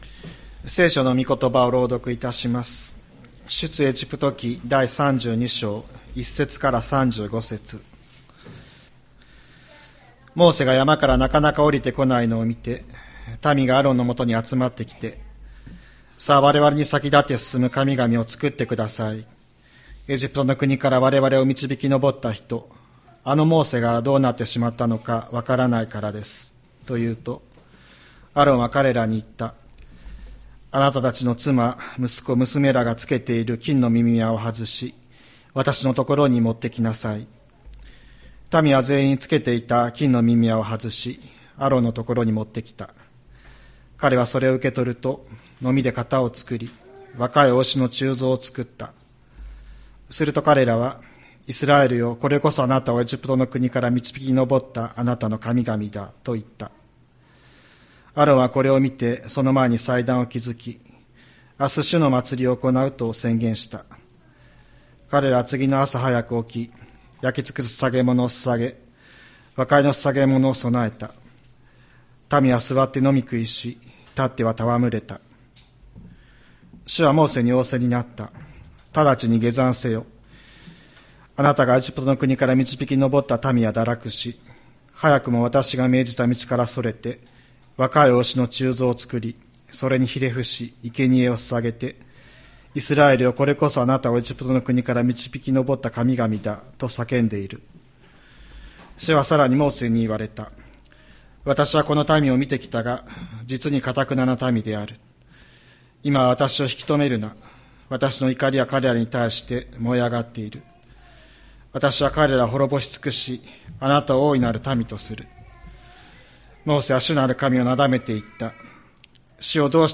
千里山教会 2025年02月16日の礼拝メッセージ。